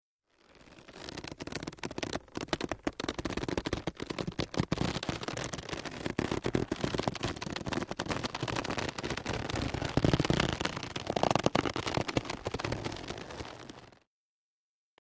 Rubber Stretching Out, X2